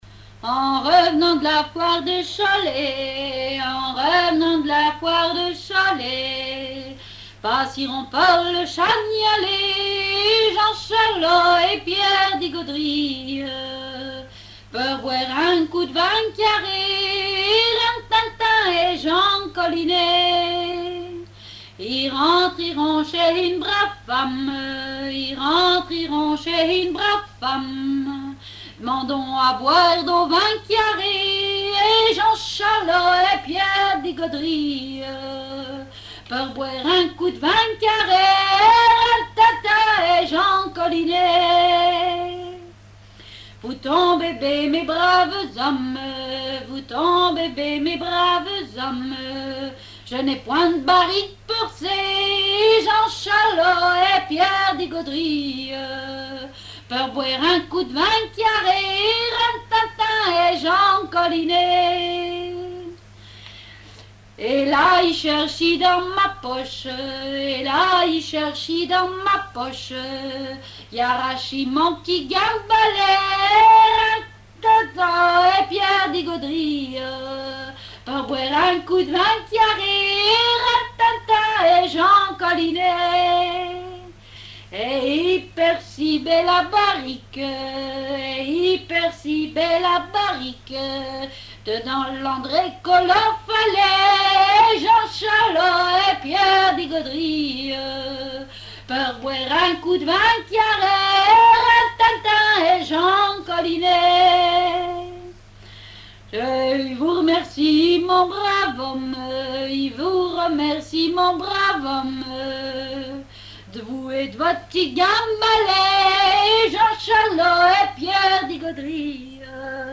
répertoire de chansons traditionnelles
Pièce musicale inédite